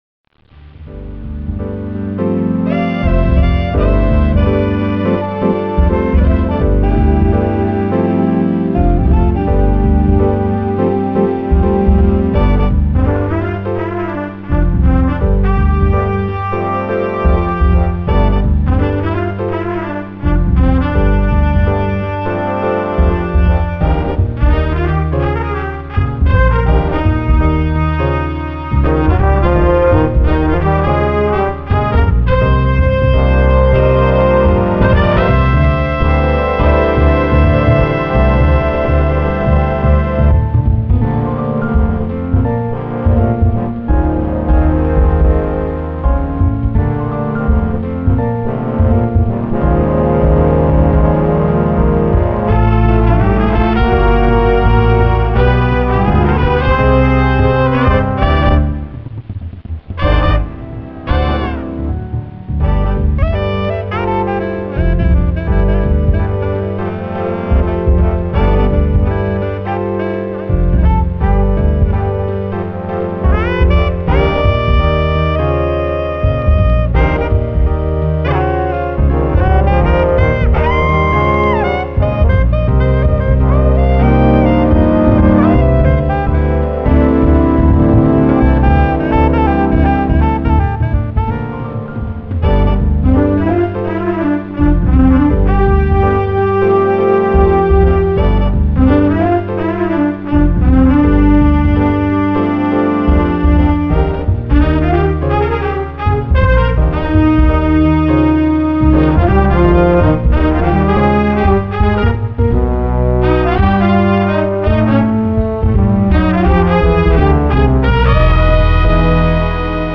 Style: Original Open Latin Shuffle Groove
Instrumentation: Standard Big Band